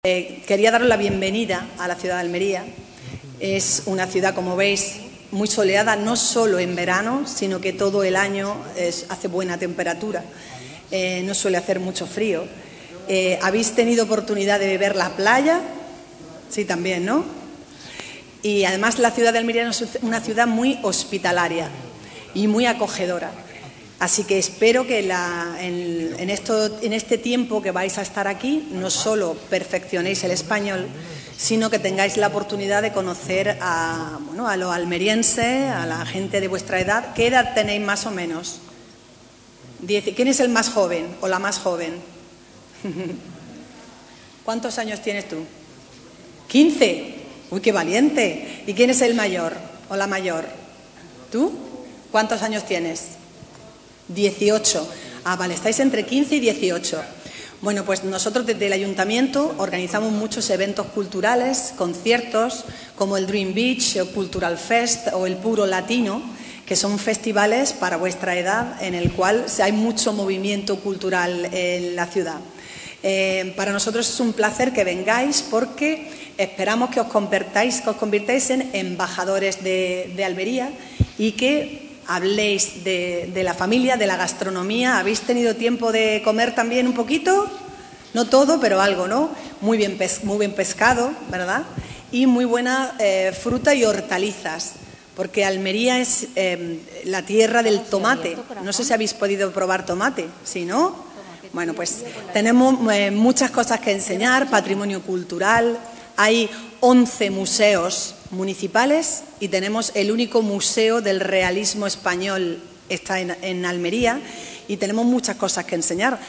CORTE-ALCALDESA-RECEPCION-ALUMNOS-UNIVERSIDAD-INDIANA.mp3